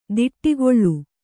♪ diṭṭigoḷḷu